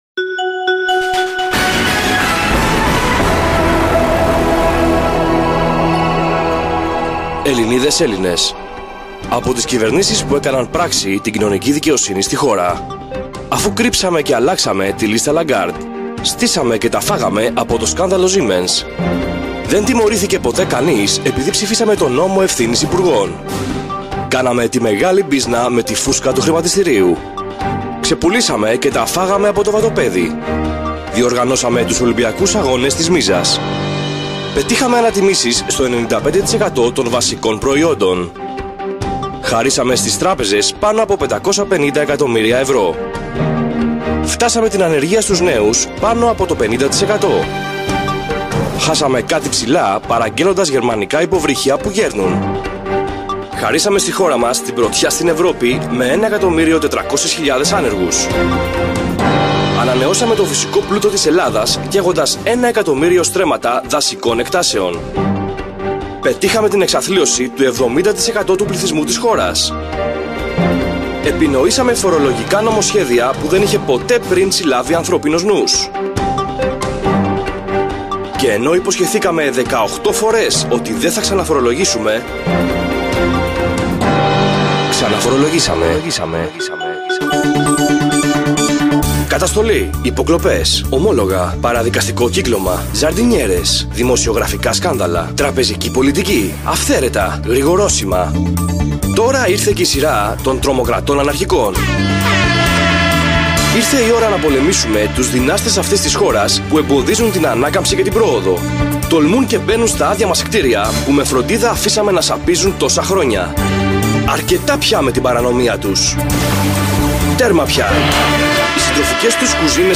Ραδιοφωνική παρέμβαση